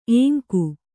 ♪ ēŋku